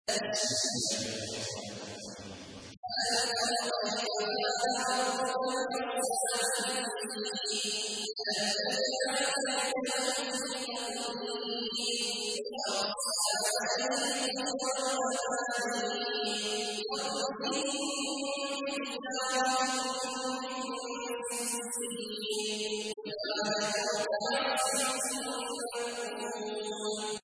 تحميل : 105. سورة الفيل / القارئ عبد الله عواد الجهني / القرآن الكريم / موقع يا حسين